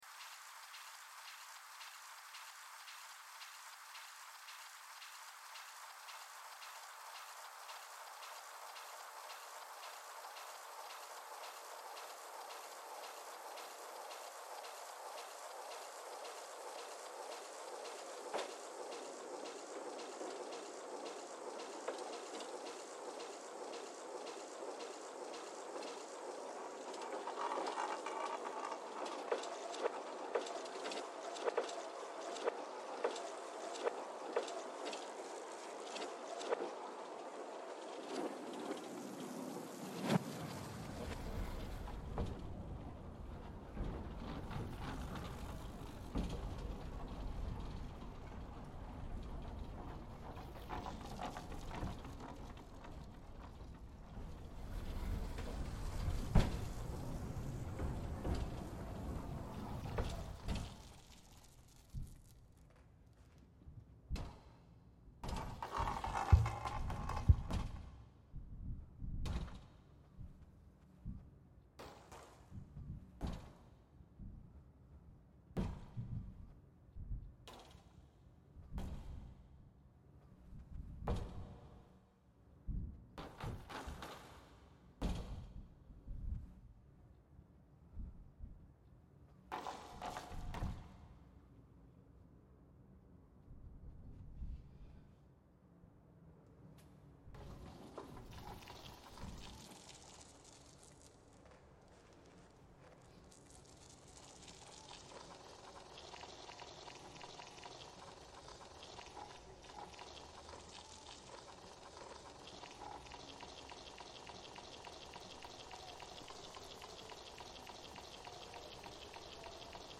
Santiago funicular reimagined